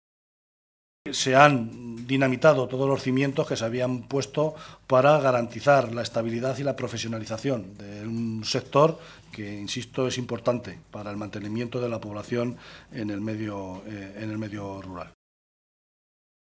Martínez Guijarro realizaba estas declaraciones en la rueda de prensa posterior a la reunión que han mantenido miembros del Grupo socialista con representantes sindicales de este sector de la región.